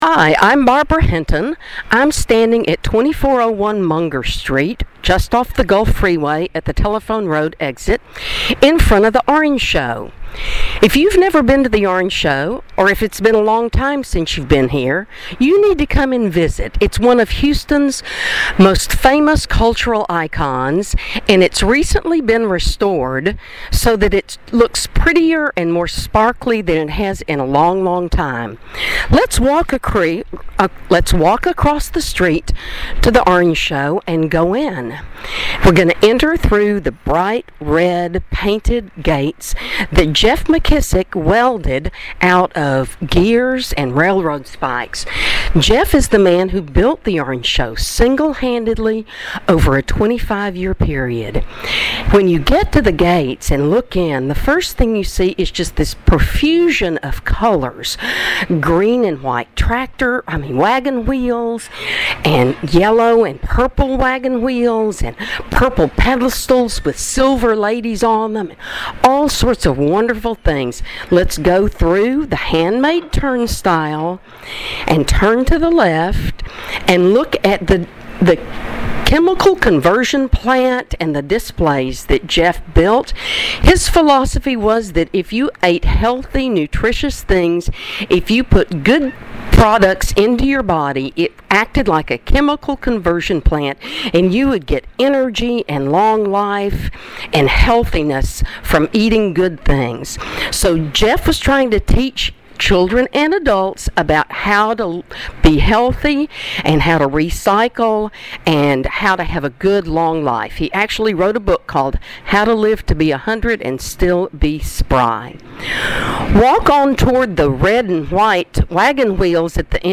audio walking tours